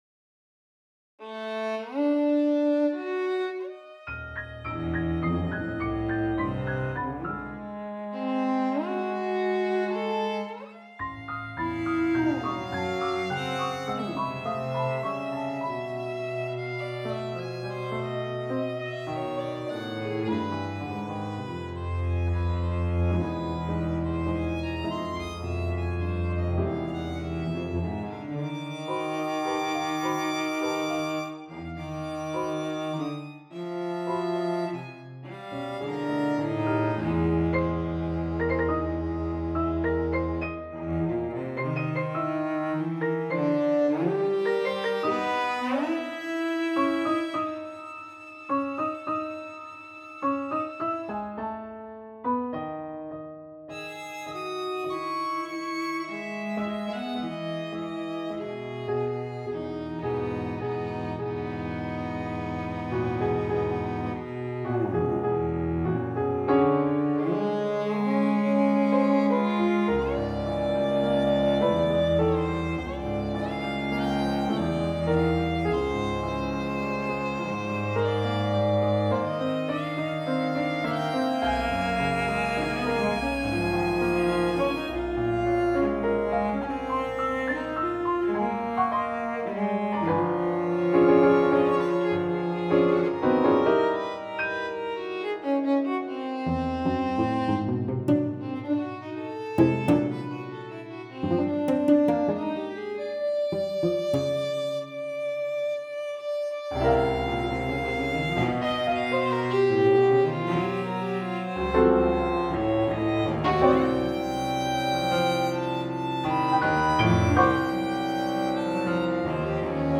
Flute, Clarinet, Piano, Violin, Cello and Percussion